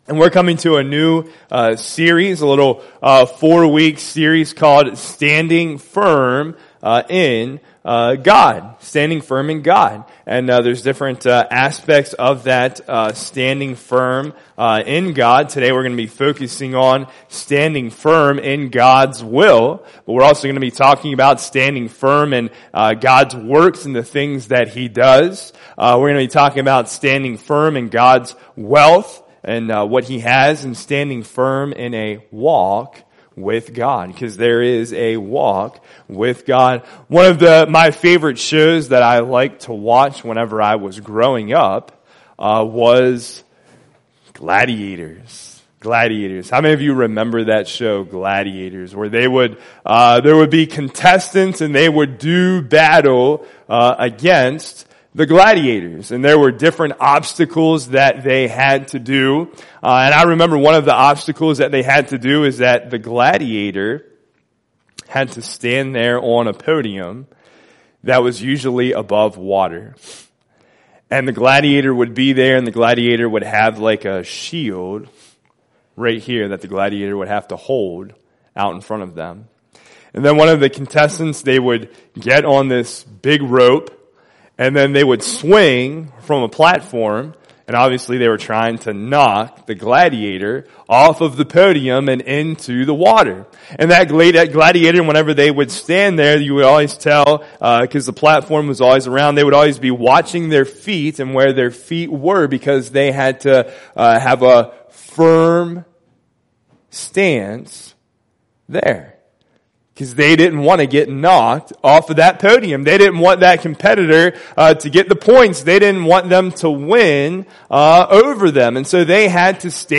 Proverbs 3:1-5 Service Type: Sunday Morning Worship Service God has a purpose and plan for your life.